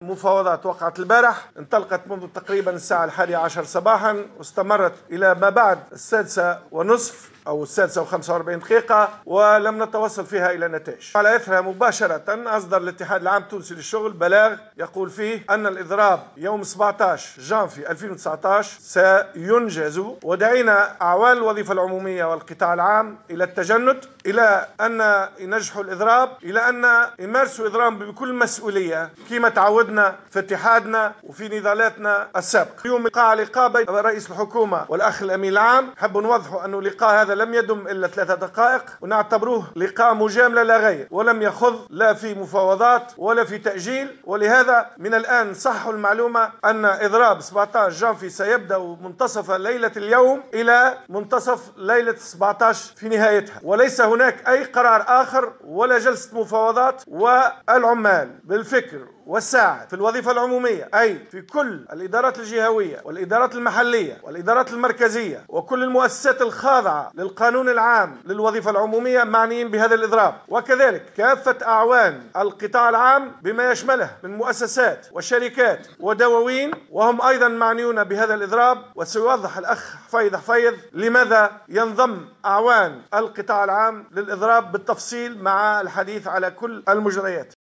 وقال خلال ندوة صحفية عقدها اليوم بتونس، ان اللقاء الذي نعتبره لقاء مجاملة، لم يخض لا في مفاوضات ولا في تاجيل للاضراب.